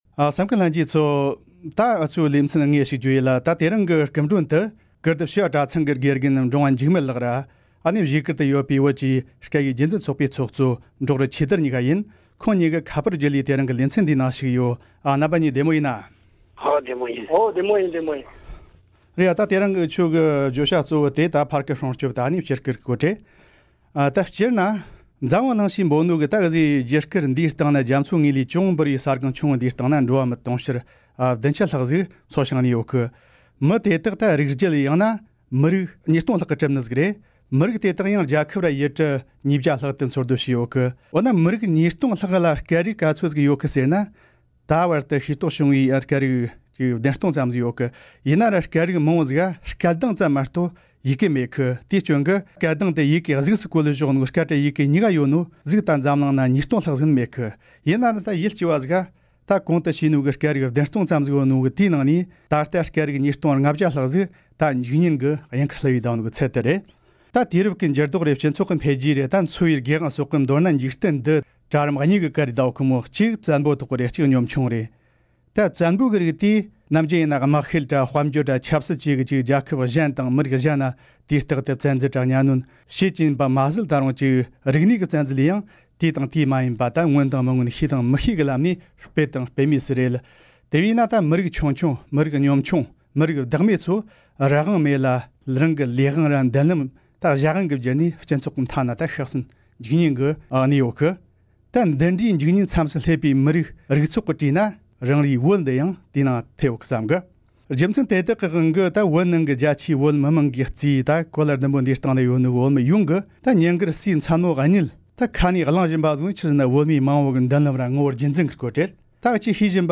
འབྲེལ་ཡོད་མི་སྣ་དང་བགྲོ་གླེང་ཞུས་པར་གསན་རོགས།